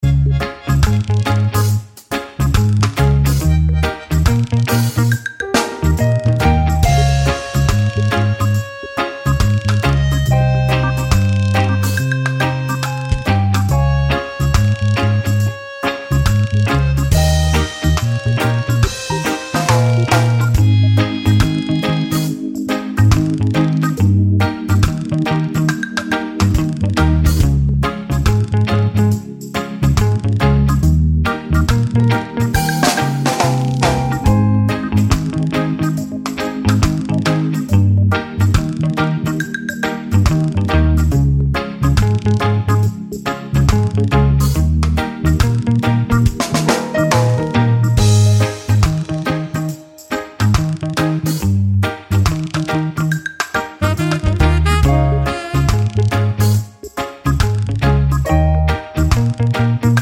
no Backing Vocals Ska 4:27 Buy £1.50